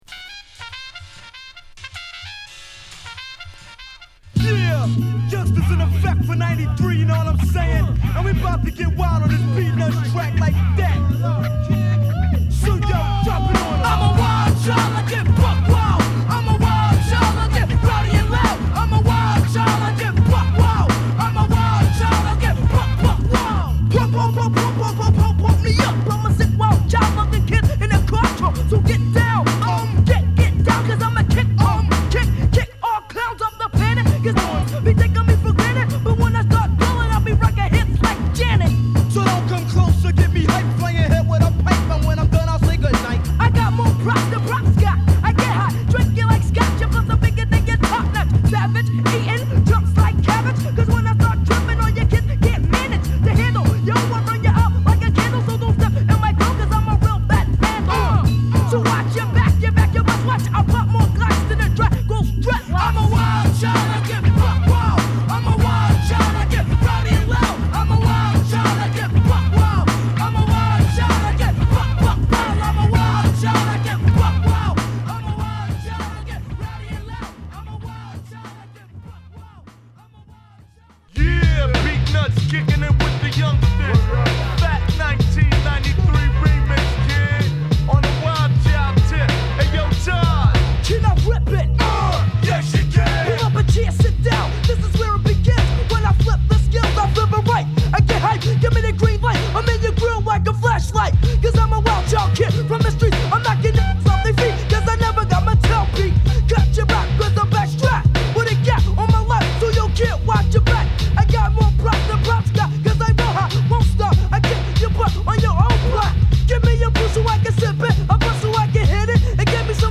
より腰を落としたヘビー・ニュースクールなRemix